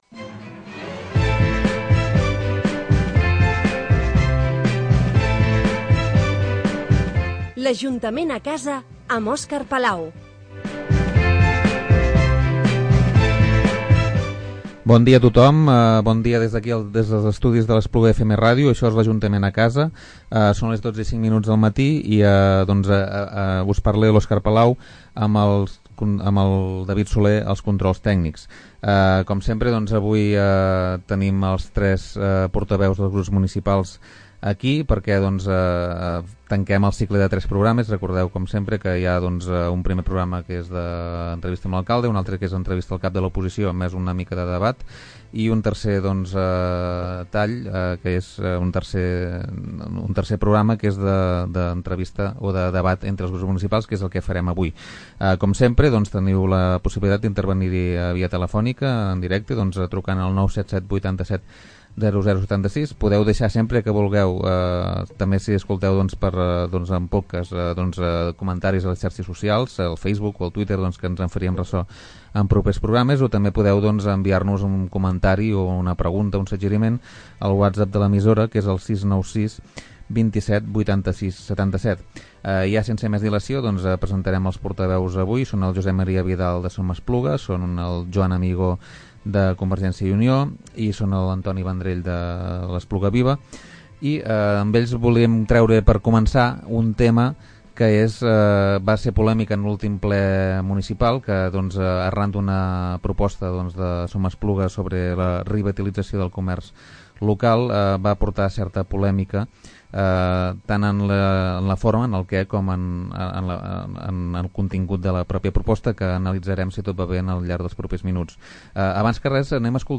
En aquest cas, el format ha estat el de debat entre els grups municipals amb representació al consistori. Així, hem comptat amb Josep M. Vidal, regidor de SOM Espluga; Joan Amigó, regidor de Convergència i Unió, i Antonio Vendrell, regidor de CP – PSC.